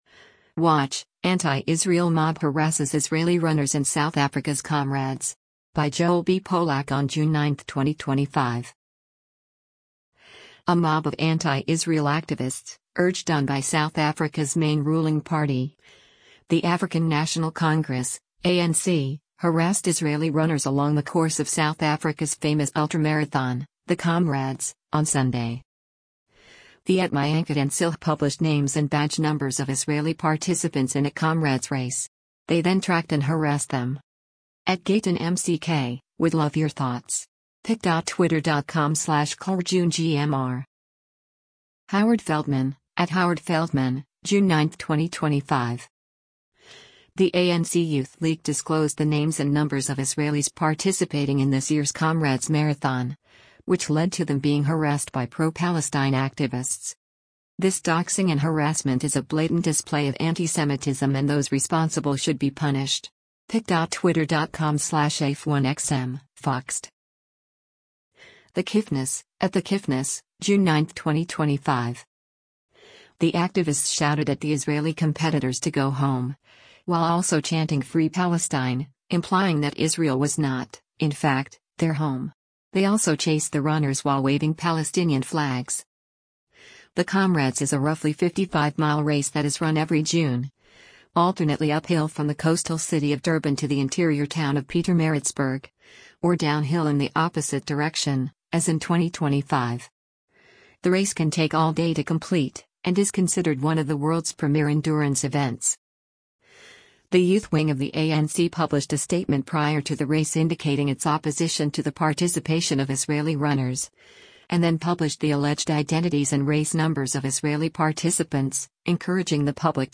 The activists shouted at the Israeli competitors to “go home,” while also chanting “Free Palestine,” implying that Israel was not, in fact, their home.